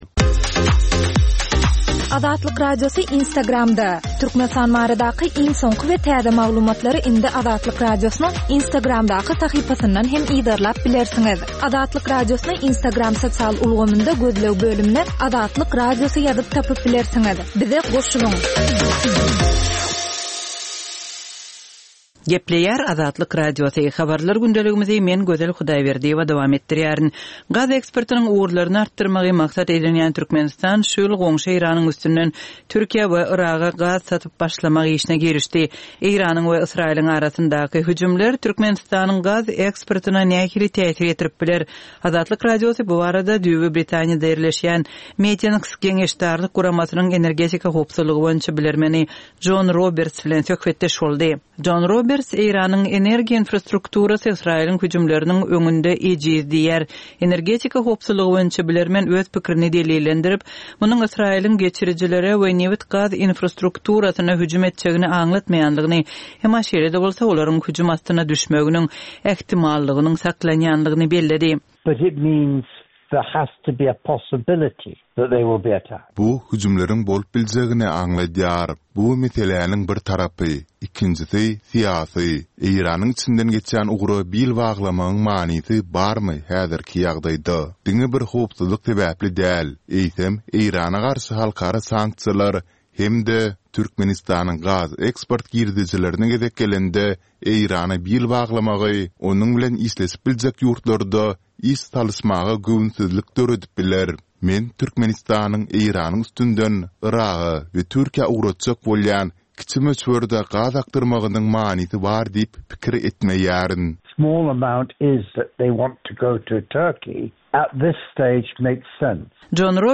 Türkmenistandaky we halkara arenasyndaky soňky möhüm wakalar we meseleler barada ýörite informasion-habarlar programma. Bu programmada soňky möhüm wakalar we meseleler barada giňişleýin maglumatlar berilýär.